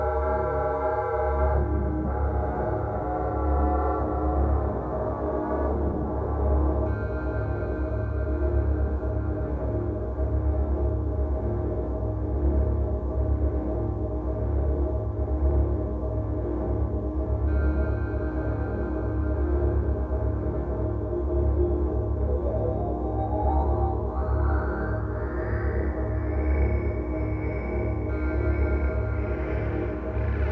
催眠性の高いアシッド・テクノを展開しています